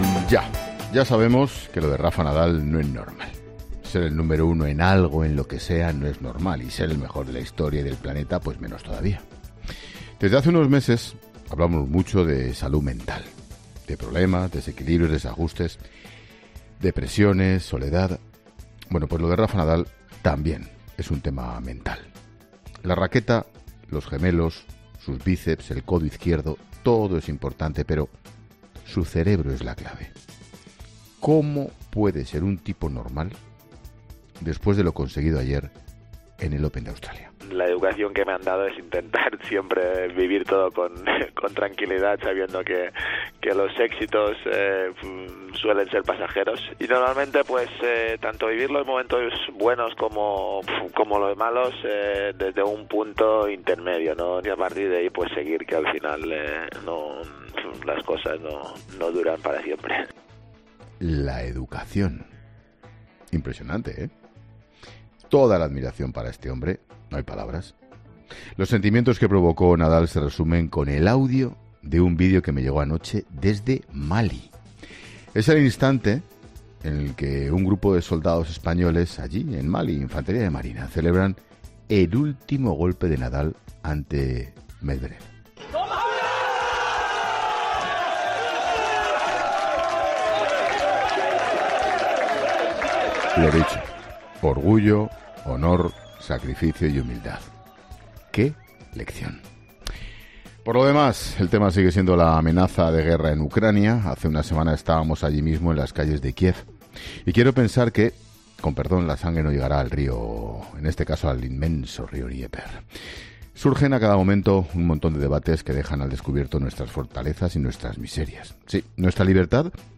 Monólogo de Expósito
El director de 'La Linterna', Ángel Expósito, reflexiona sobre las principales noticias que deja este lunes 31 de enero